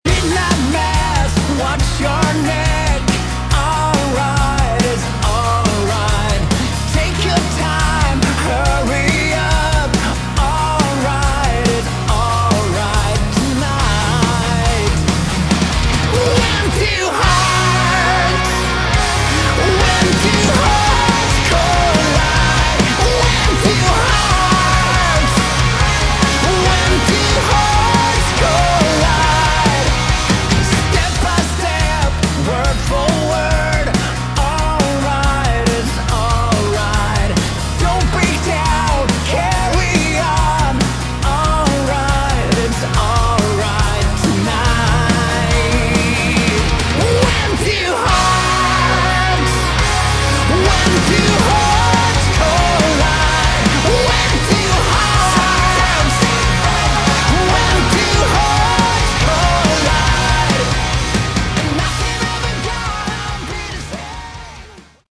Category: Melodic Hard Rock
vocals
guitars
drums
bass